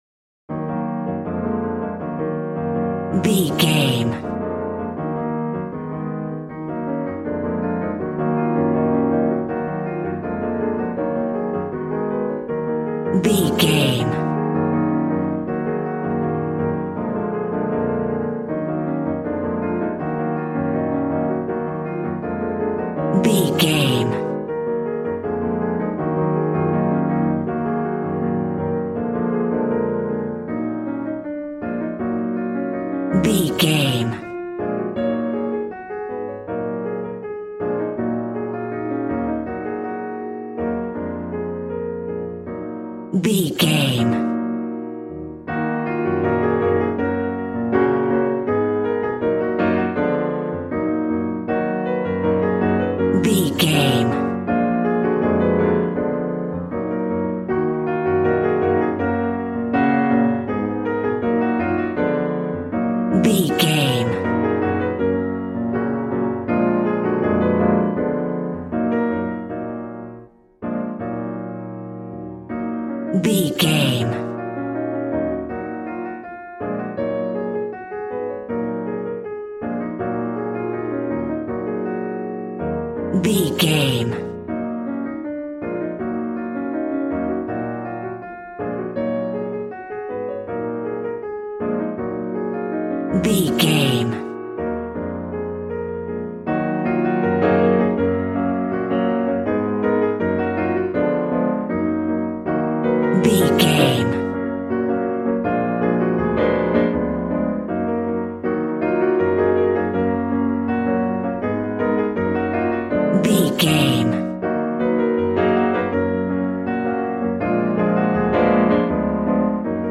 An exotic and colorful piece of Espanic and Latin music.
Aeolian/Minor
passionate
acoustic guitar